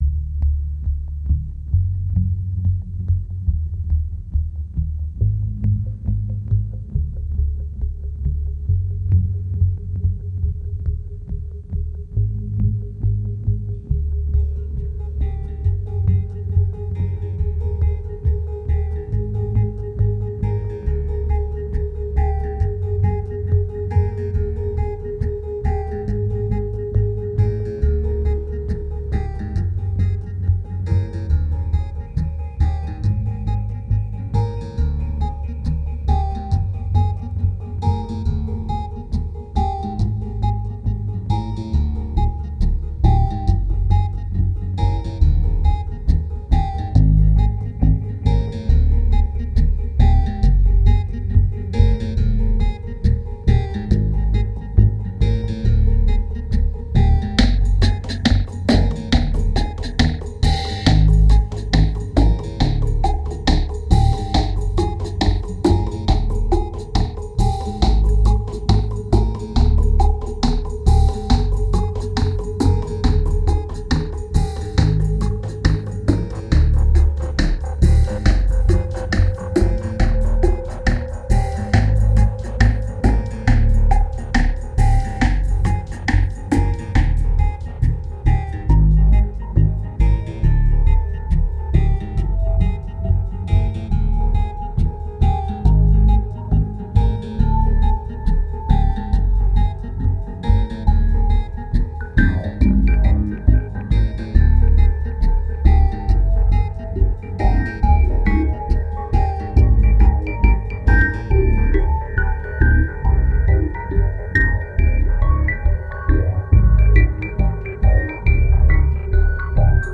2178📈 - -45%🤔 - 69BPM🔊 - 2010-10-18📅 - -338🌟